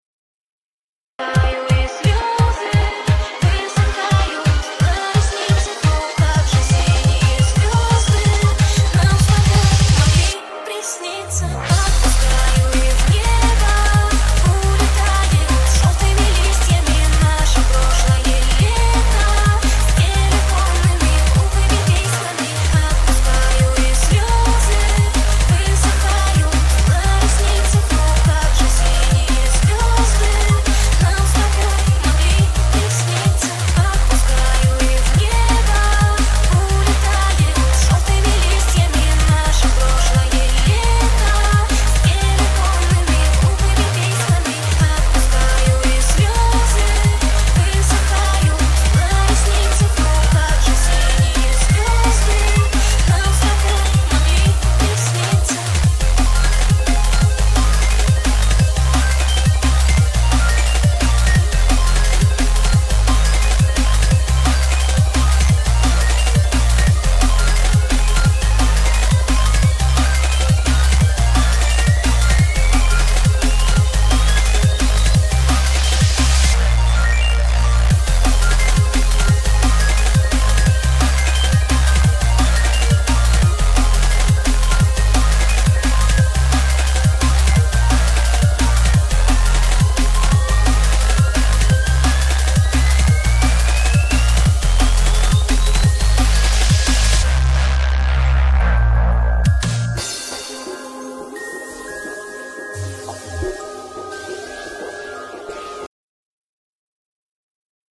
muzyka rosyjska popularna lub coś takiego
techno elektro lub coś takiego nie znam się